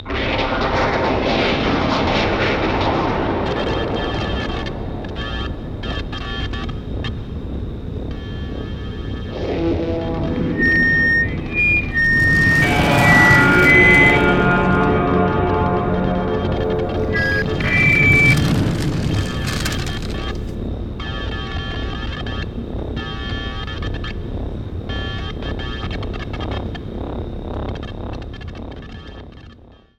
These were mostly put together with royalty-free sound effects (from Freesound and FilmCow's pack), and a few sounds were recorded by me!
Titan Speaker core ambience (infected) (plain file link)
No footsteps or pet-pats for this one.
This one's shorter than the others because it's not very pleasant to listen to, and I was starting to feel really sorry for Titan Speaker!
• atmospheric noise
• big shelves rotated
• feedback
• space horn
core_tspeaker_infected.mp3